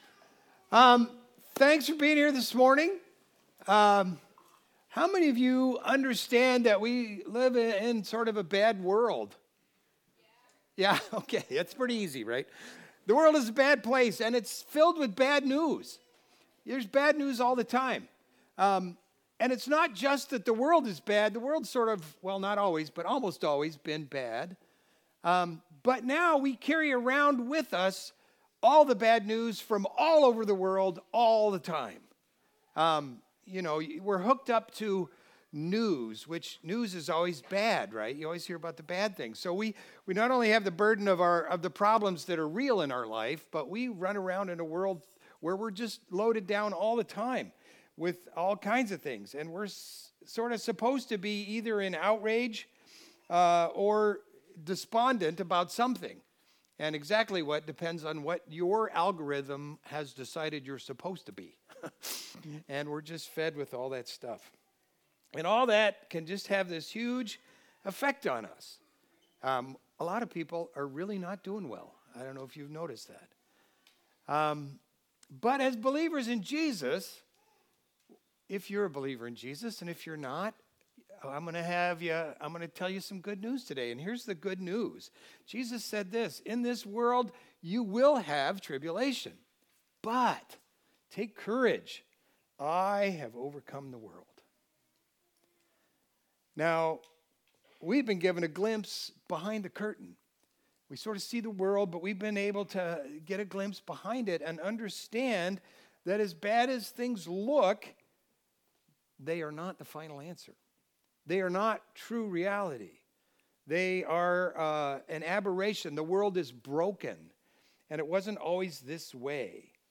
Video Audio Download Audio Home Resources Sermons Let's Be Merry Dec 21 Let's Be Merry Christmas gives us many reasons to truly be filled with joy.